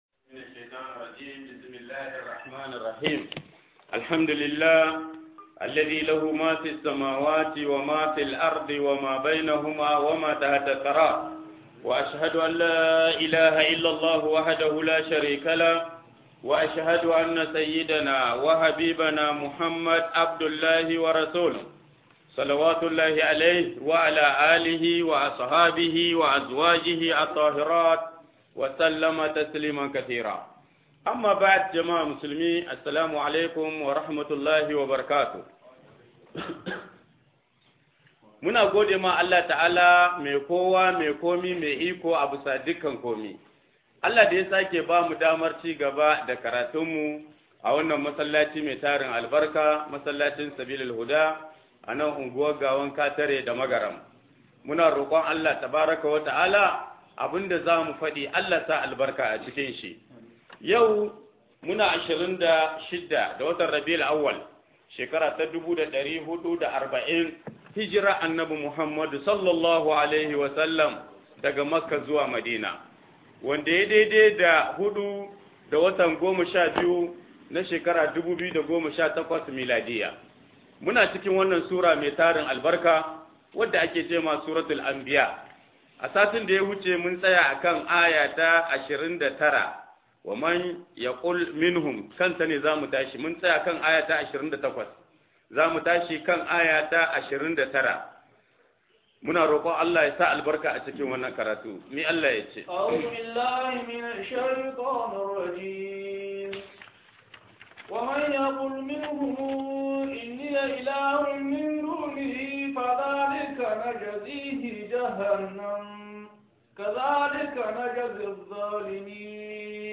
Tafsirin Alqur'ani Na Mako Mako